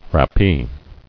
[rap·pee]